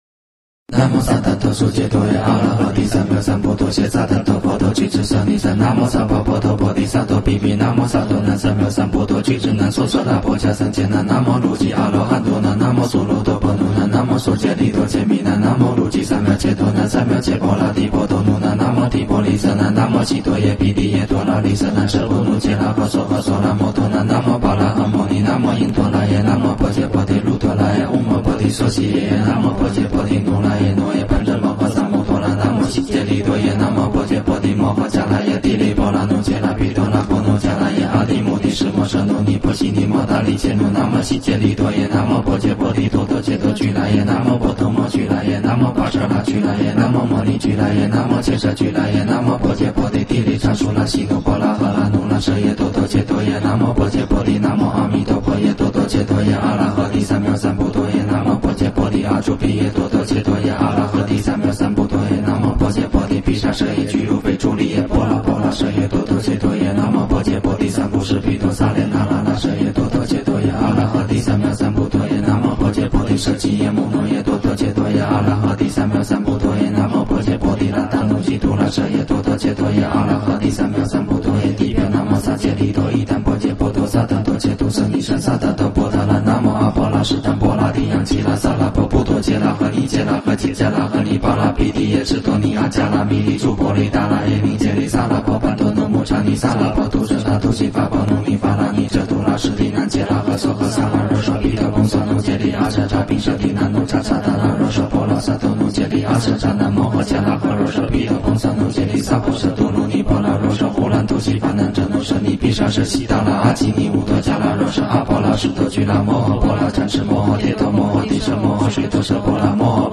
楞严咒快诵